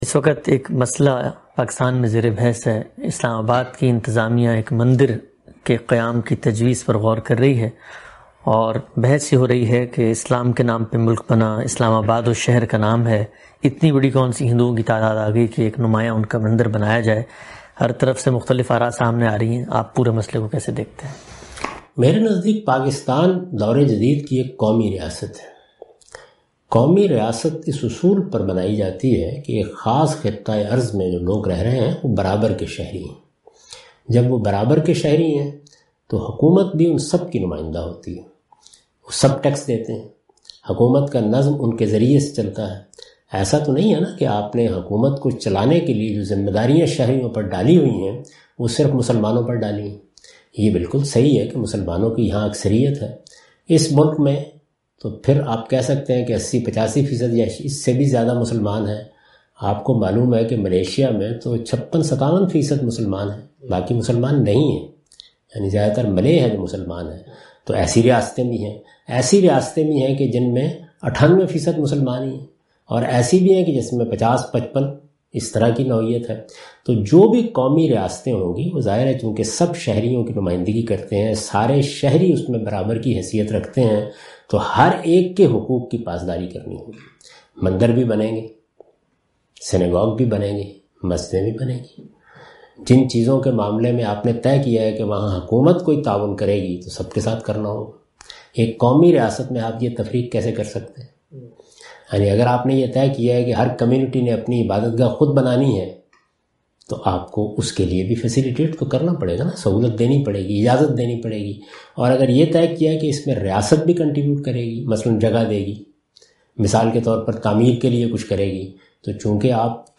Category: Reflections / Questions_Answers /
In this video, Mr Ghamidi answer the question about "Should a Hindu Temple be constructed in Pakistan?".